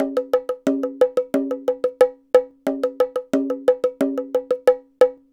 Bongo 18.wav